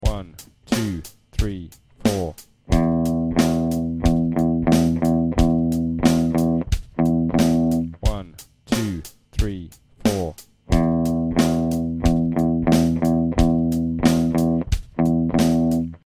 Grade 3 Rhythmic Recall Audio Samples
These are a 2 bar rhythm using the E notes on the 6th string of the guitar.